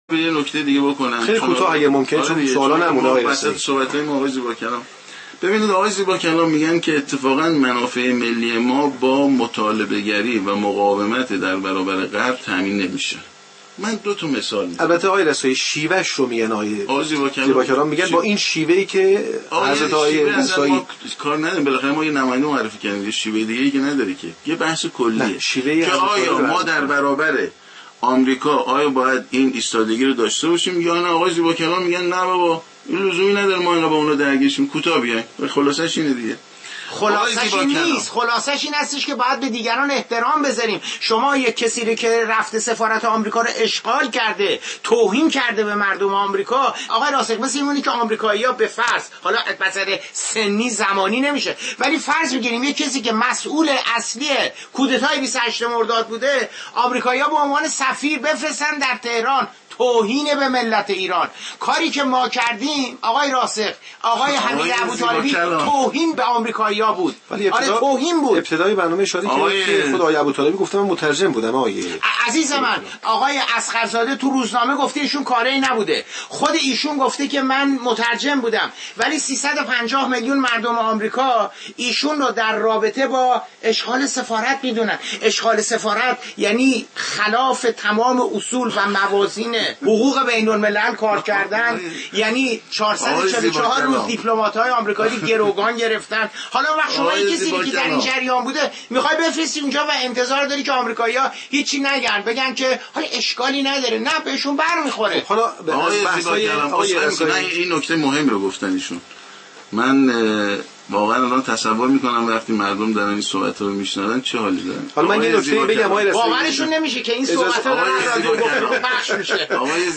بخشی از مناظره آقای رسایی و آقای ریباکلام